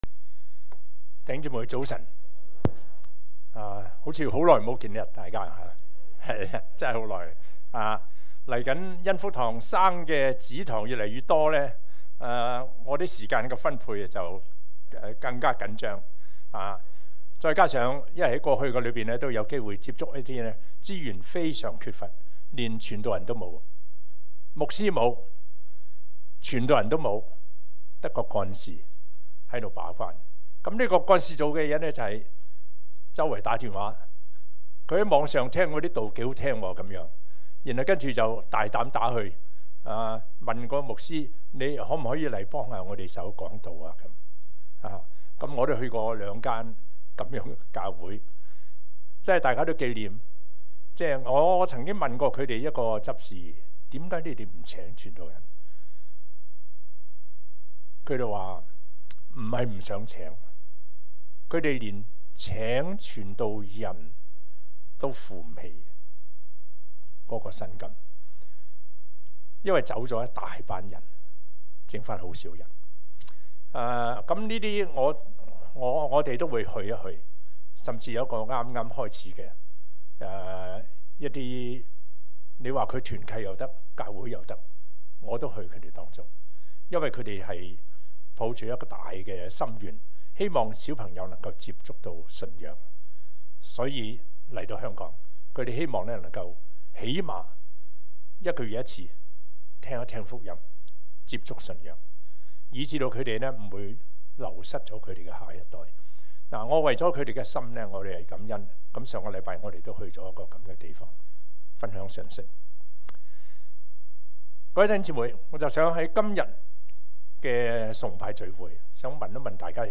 證道重溫
恩福元朗堂崇拜-早、中堂